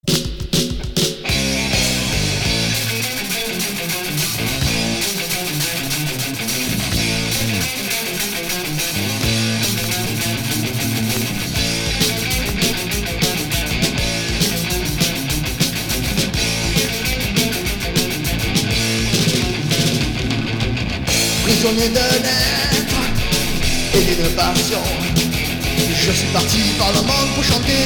Hard heavy